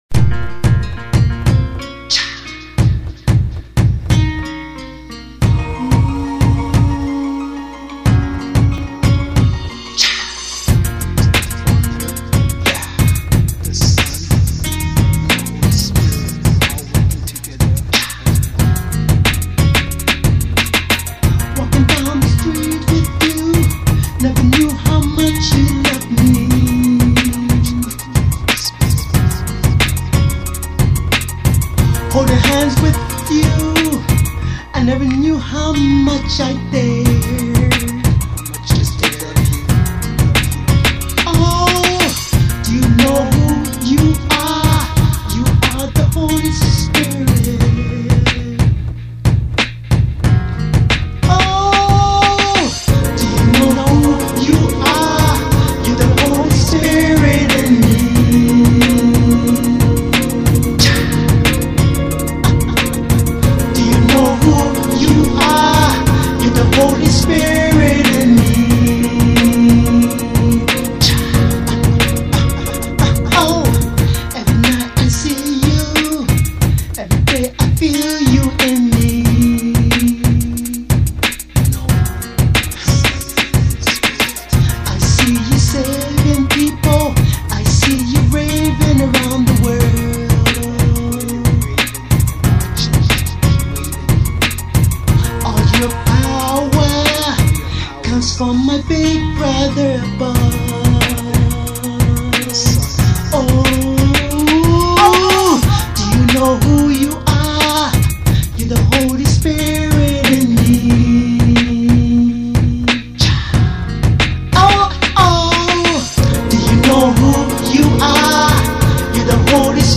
dance/electronic
SOUL FULL
RnB
Hip-hop